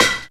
• Clear Snare Single Hit B Key 41.wav
Royality free acoustic snare tuned to the B note. Loudest frequency: 2793Hz
clear-snare-single-hit-b-key-41-nZx.wav